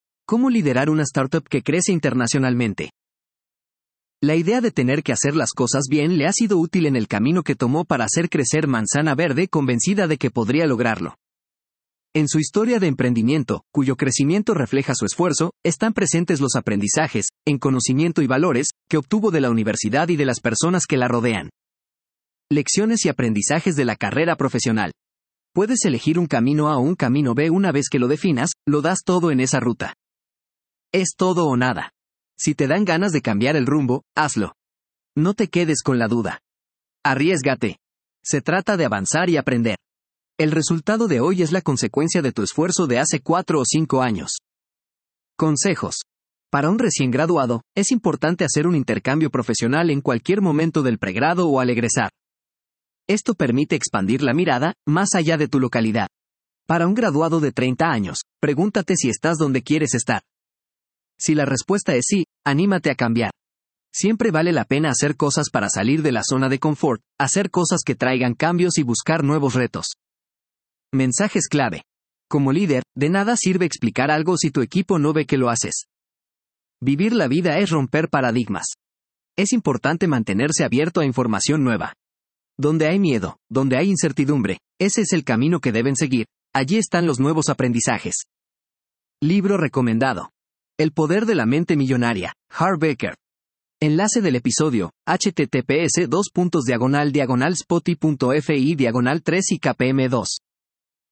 Lector implementado por DIRCOM ● Universidad de Piura